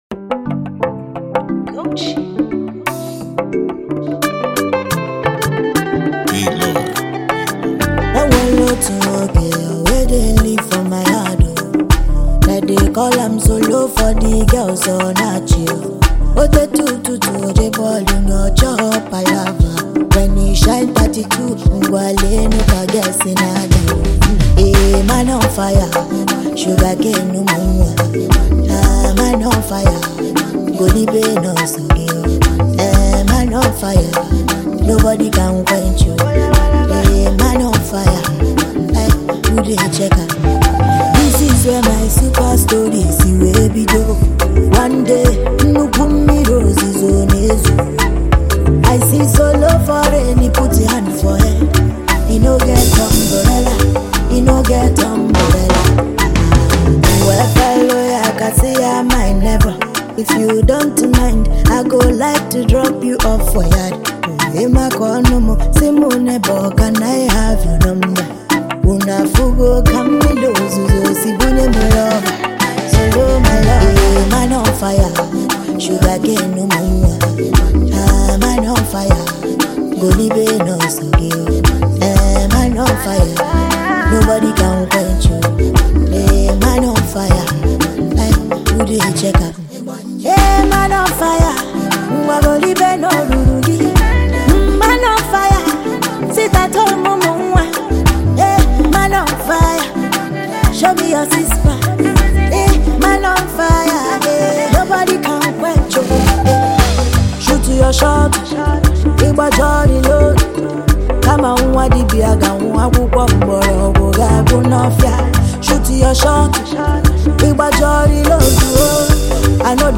Nigerian rapper, singer, and songwriter
catchy song